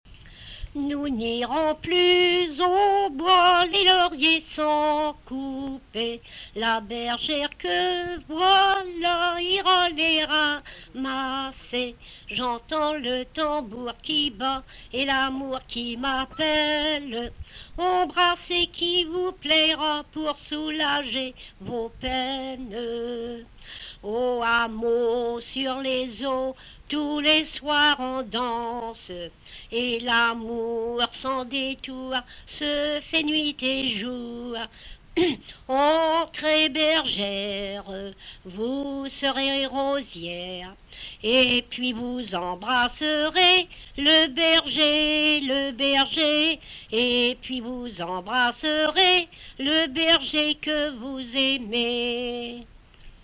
Chanson Item Type Metadata
Emplacement Miquelon